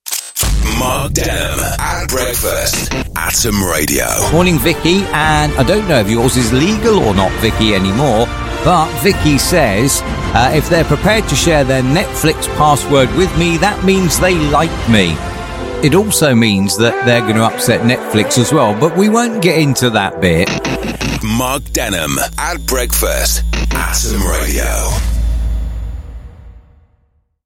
Atom Radio Breakfast Show